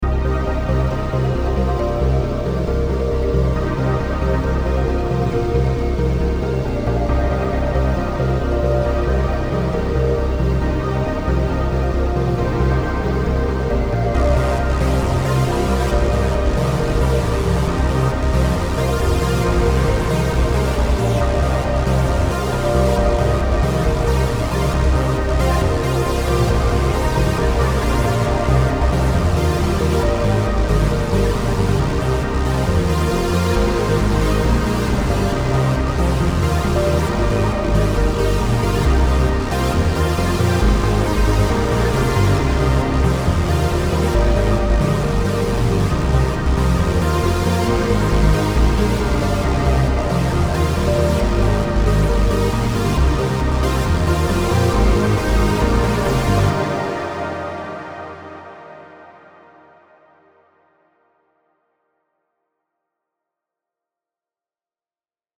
I used all loops except for the drumbeat, which I did on my electronic drum set.